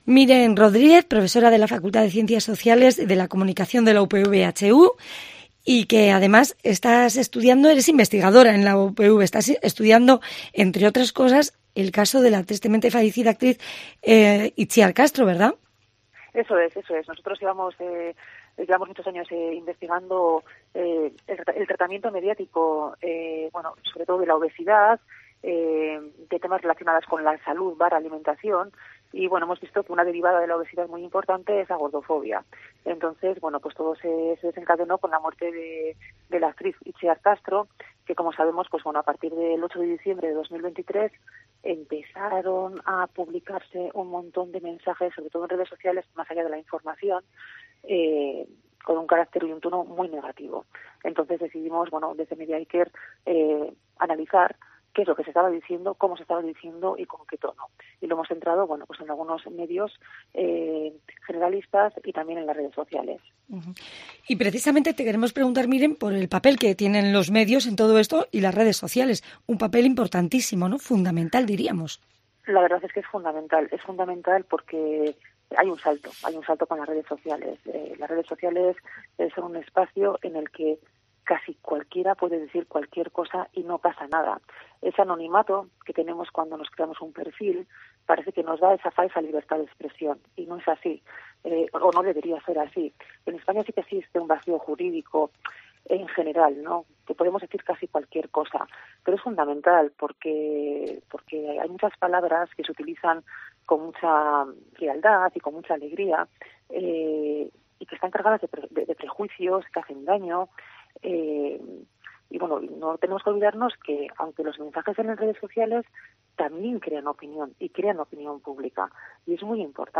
COPE Euskadi habla con dos expertas sobre el estigma social de las personas con más peso
COPE entrevista a expertas en gordofobia